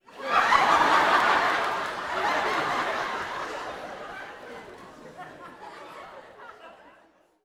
Audience Laughing-08.wav